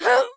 damage_4.wav